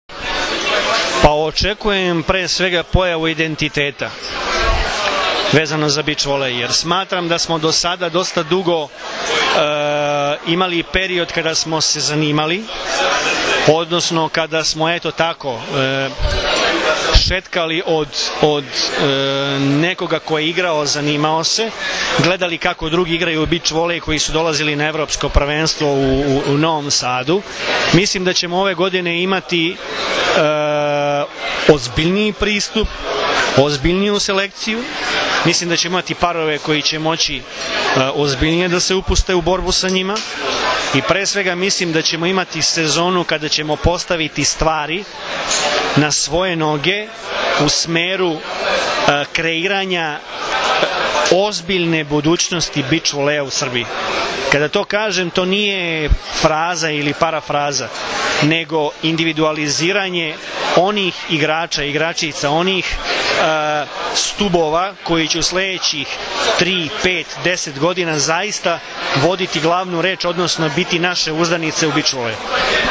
U beogradskom restoranu „Dijagonala” danas je svečano najavljen VI „Vip Beach Masters 2013.“ – Prvenstvo Srbije u odbojci na pesku, kao i Vip Beach Volley liga, u prisustvu uglednih gostiju, predstavnika gradova domaćina, odbojkašica, odbojkaša i predstavnika medija.
IZJAVA VLADIMIRA GRBIĆA, POTPREDSEDNIKA OSS